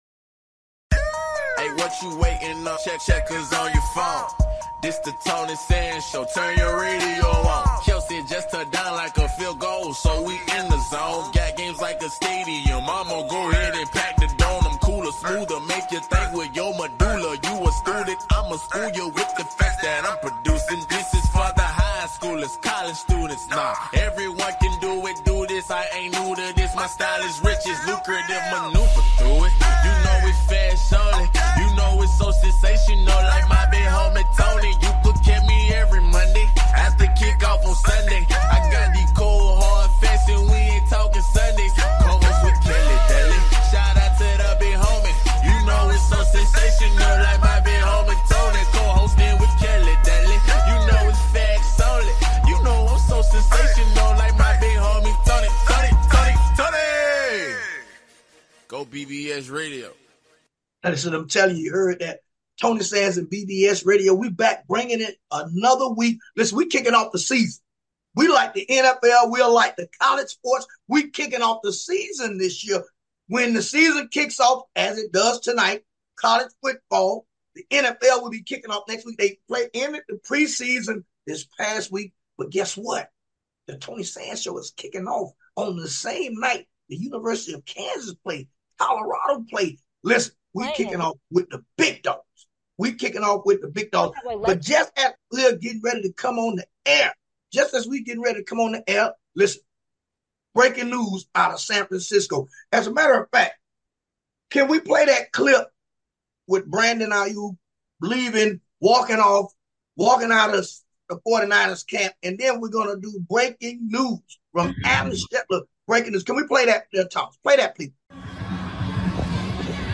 With Guest, Michael Irvin, Former Dalas Cowboys Wide Receiver